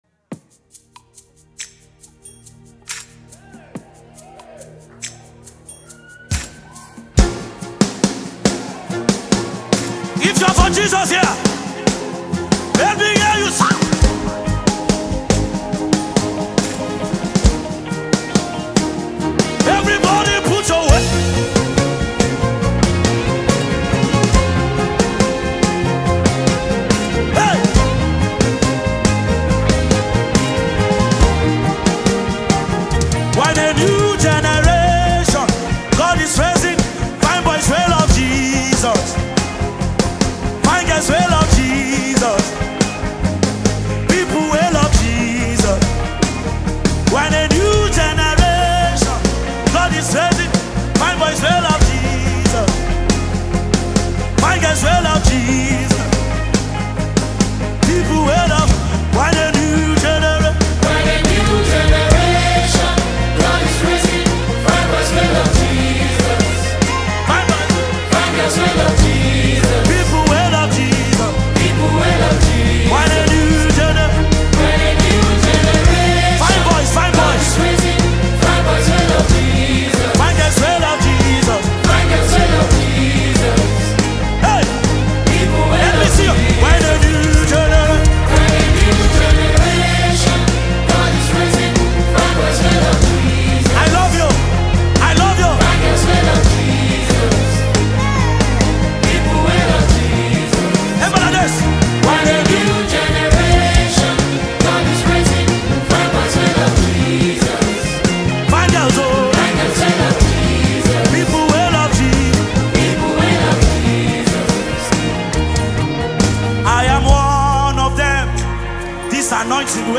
a highly talented Nigerian gospel singer and songwriter
inspirational song